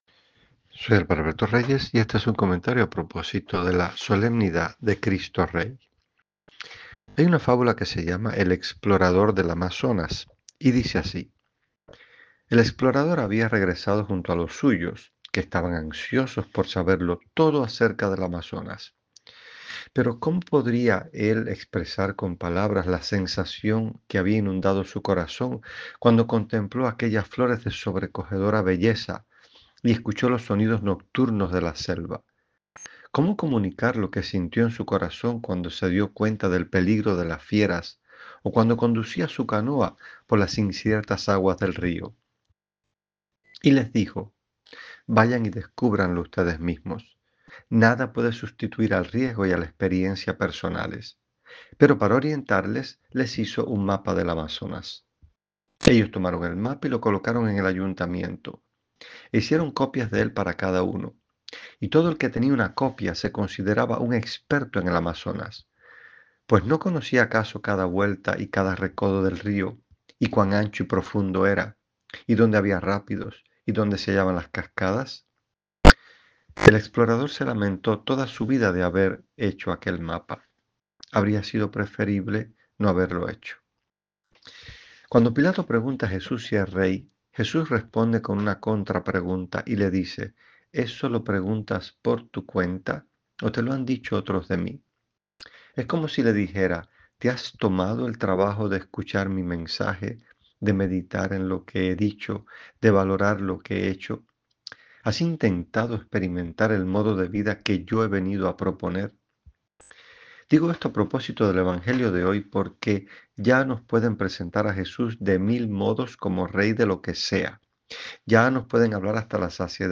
En su voz: